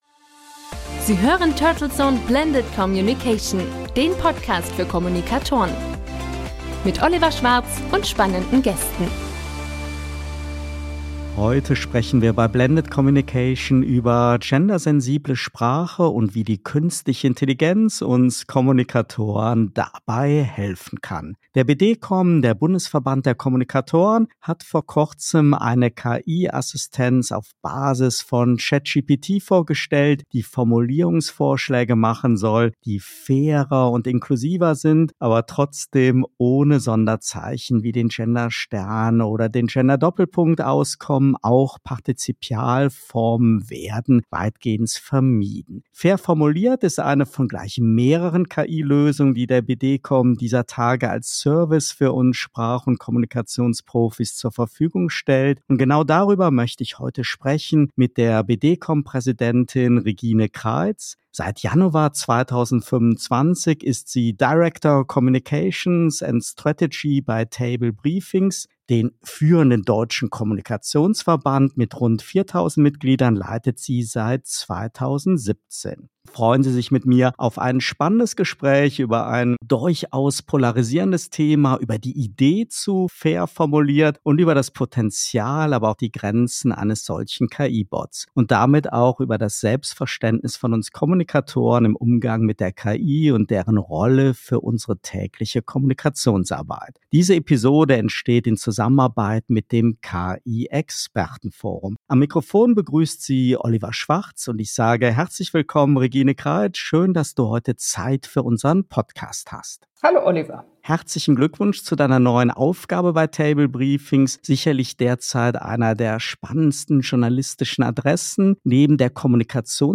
Im Mittelpunkt des Talks stehen Kommunikationsstrategien sowie Trends und Herausforderungen in der Welt der PR und Unternehmenskommunikation.